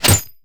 snd_ceroba_shield_impact.wav